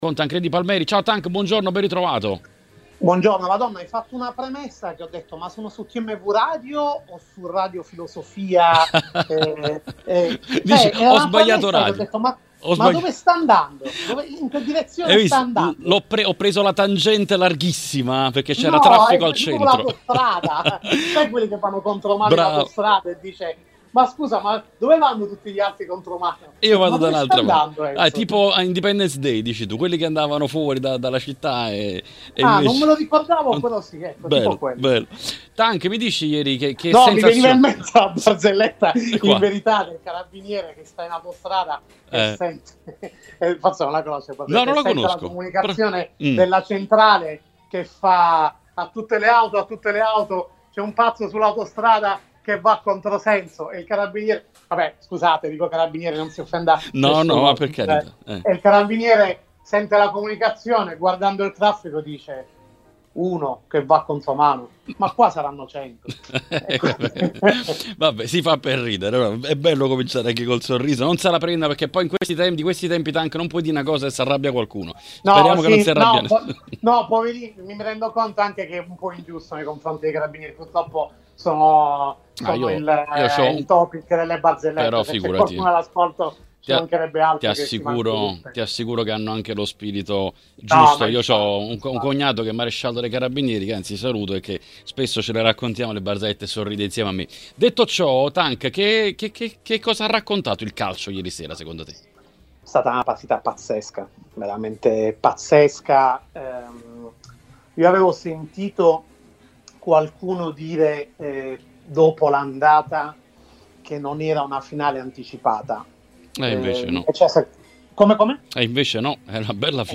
ospite nell’editoriale di TMW Radio: